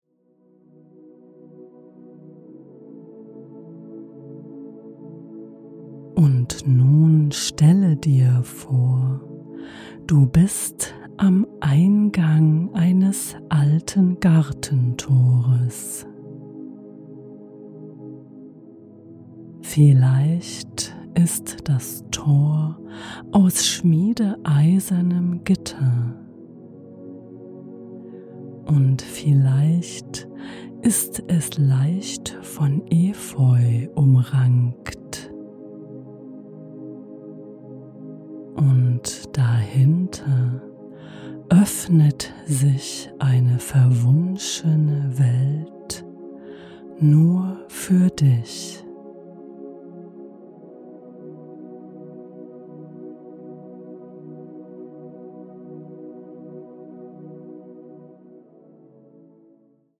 Fantasiereise Im Rosengarten - Via Fantasia geführte Meditationen